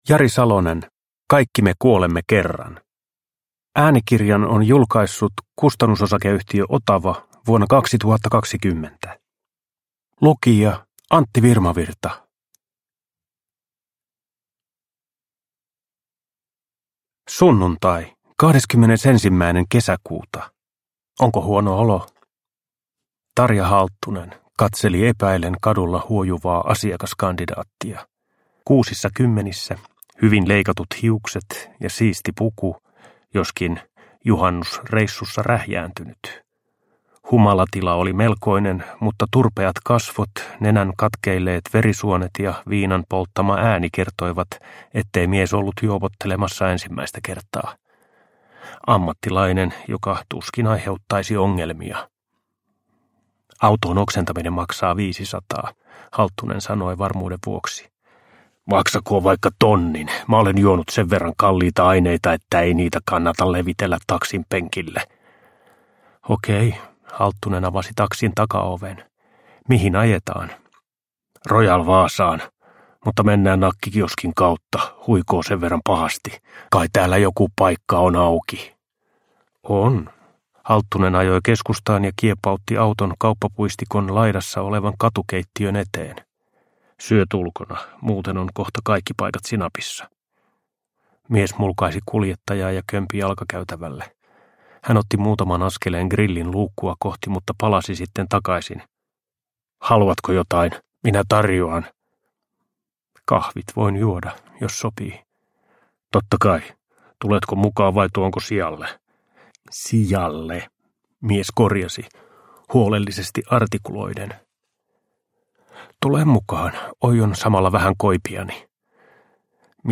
Kaikki me kuolemme kerran – Ljudbok – Laddas ner
Uppläsare: Antti Virmavirta